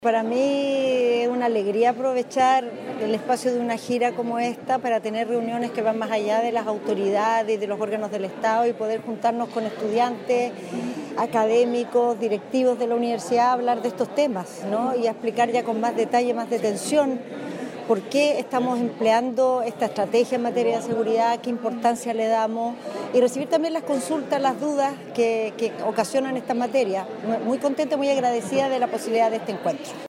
En medio de la apretada agenda de su visita al Biobío, la ministra Carolina Tohá llegó hasta la Universidad de Concepción para participar del seminario “Futuro y seguridad pública en Chile”, organizado por la Facultad de Ciencias Jurídicas y Sociales, oportunidad en la que pudo exponer sobre la situación del país en seguridad y los desafíos y avances del Gobierno en dicha materia ante un público compuesto principalmente por académicos, funcionarios y estudiantes.